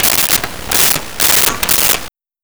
Shotgun Pumps 01
Shotgun Pumps 01.wav